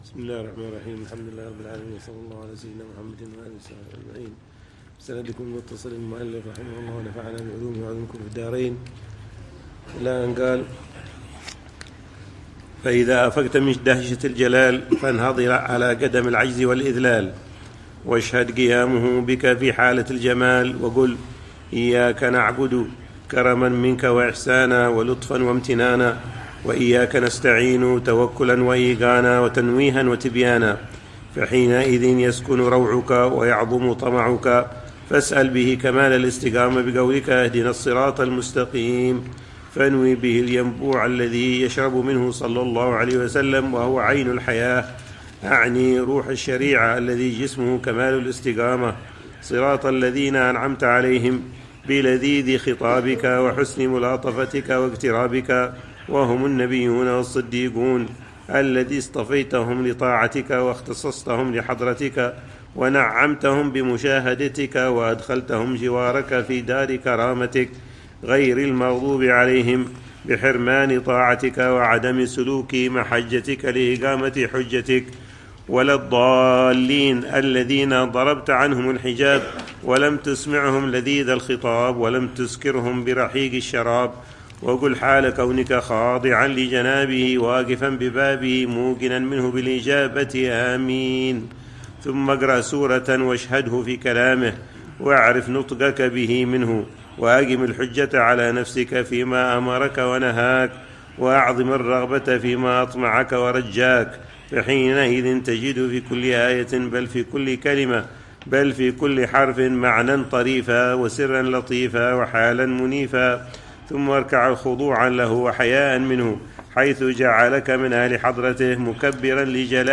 الدرس الثالث من شرح العلامة الحبيب عمر بن حفيظ لكتاب صفة صلاة المقربين للعلامة الحبيب الحسن بن صالح البحر الجفري رحمه الله، يوضح فيها صفة صلاة